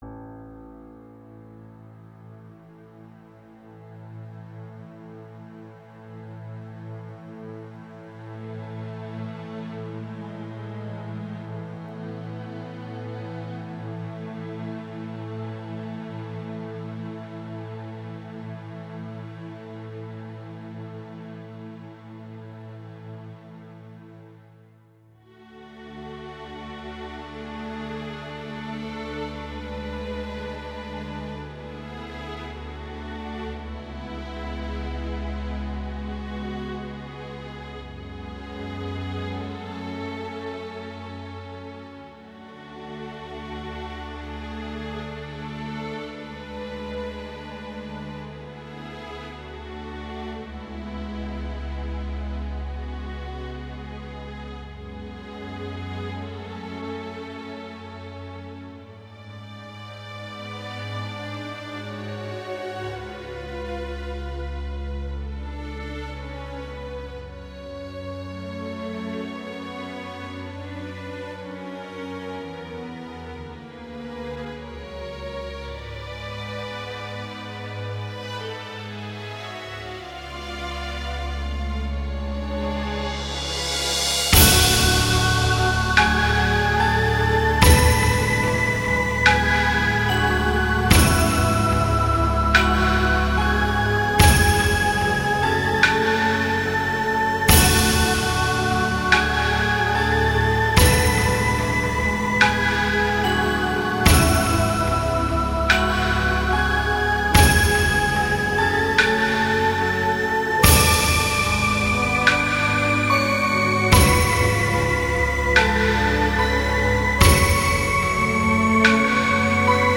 低沉的打击乐仿佛敲响了永乐朝的大钟
梦醒时分，大幕落下，看尽世事，洗尽铅华，带着丝丝感伤、点点无奈，乐声渐渐隐去。